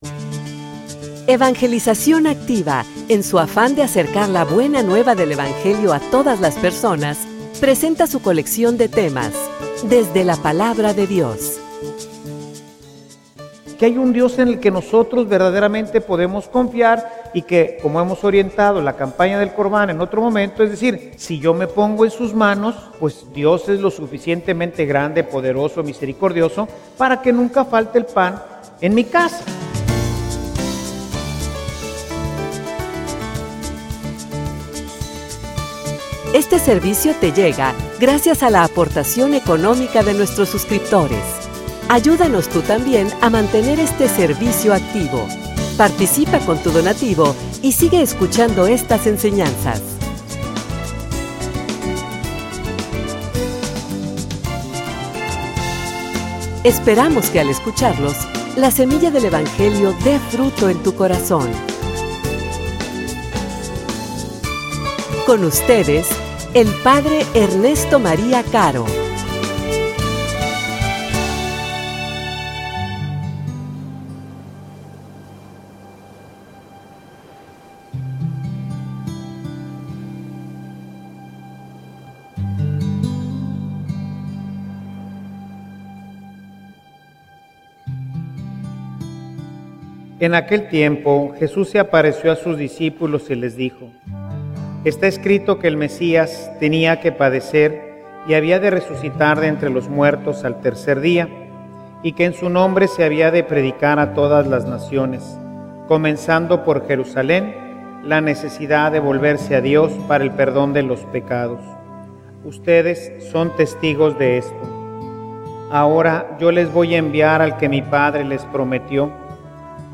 homilia_Aprender_a_esperar.mp3